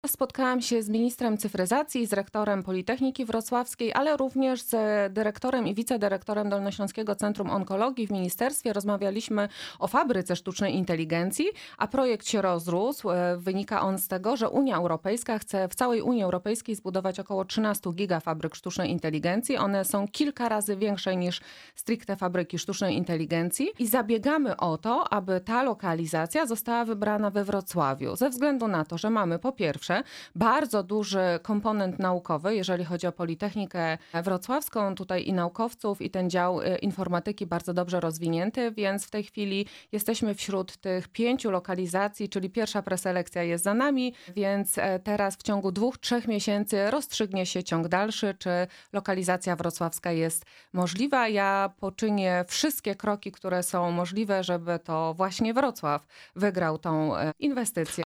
Naszym „Porannym Gościem” była posłanka na Sejm Koalicji Obywatelskiej Anna Sobolak.